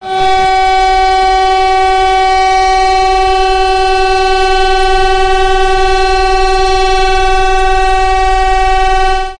Así suenan las sirenas de emergencia
El sonido de alerta consta de 3 tramos de un minuto de duración, con sonido ascendente, separados por intervalos de 5 segundos de silencio. El de fin de la alerta es una señal continuada de 30 segundos.